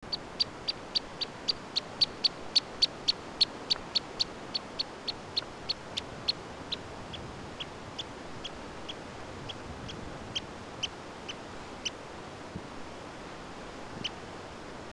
Caminera Común Curutié Blanco
camineracomun.mp3